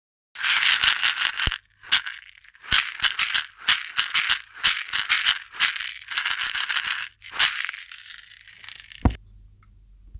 特徴は、身の回りの自然素材を使い全て手作りであること、 人に優しい「倍音」をたっぷり含んだサウンドだということです。
大きめのバスケットシェイカー(ガラガラ)です。ナチュラルな水草と底にひょうたんを組み込んでいるため、振るとジャラジャラカラカラと響きのよい心地よいサウンドがします。
素材： 水草 ヒョウタン 種 小石